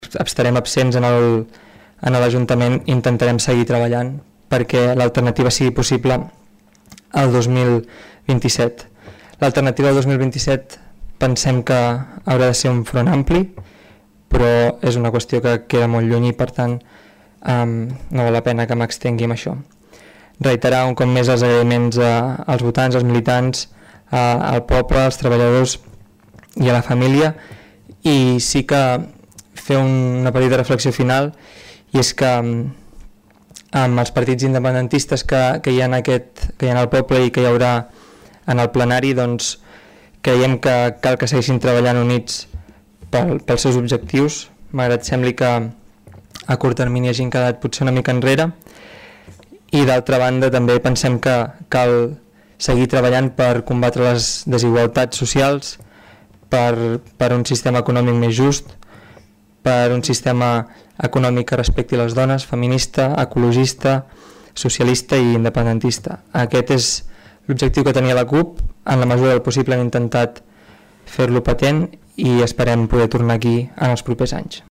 Tots els regidors que plegaven també van realitzar un darrer missatge d’acomiadament.